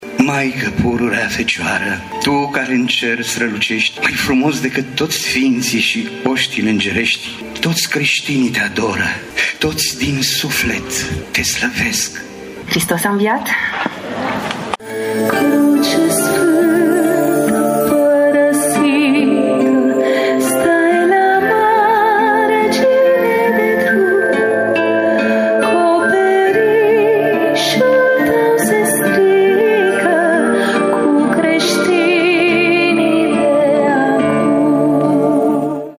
Casa de Cultură ”Mihai Eminescu” din Tîrgu-Mureș a fost, aseară, gazda spectacolului de muzică și poezie religioasă ”La umbra crucii Tale”.